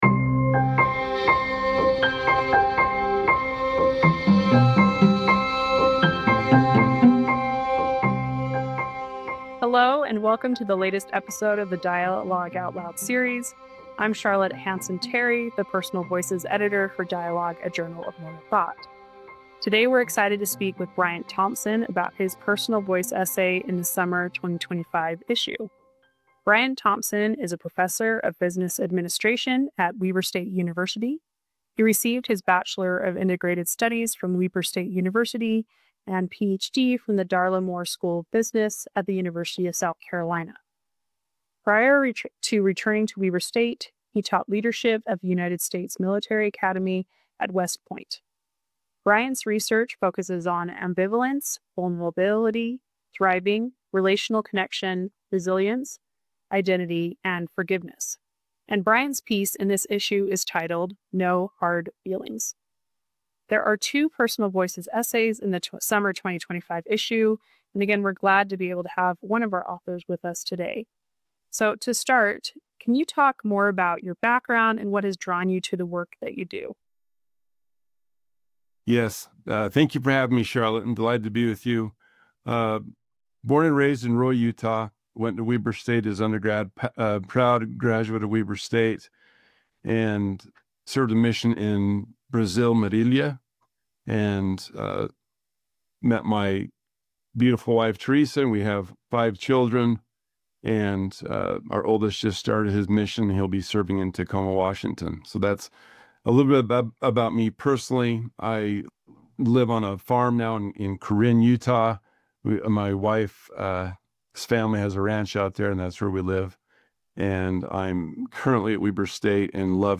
Sacred Suffering: A Conversation